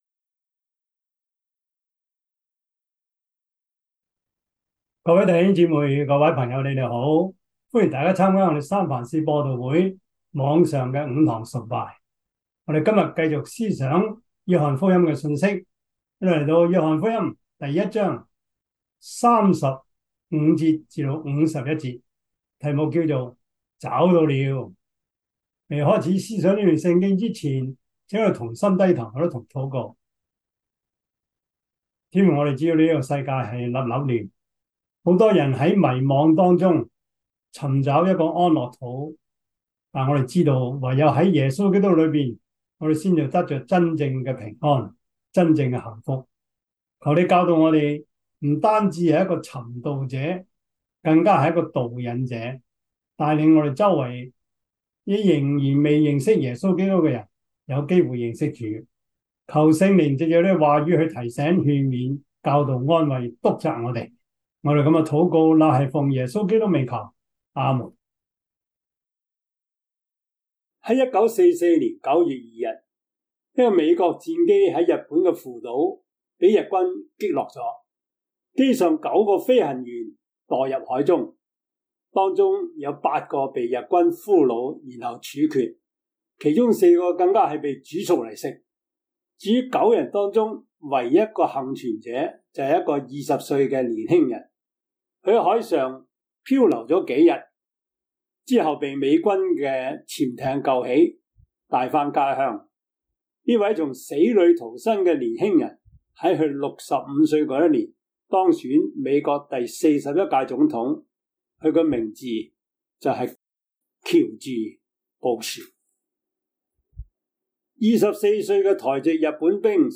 約翰福音 1:35-51 Service Type: 主日崇拜 約翰福音 1:35-51 Chinese Union Version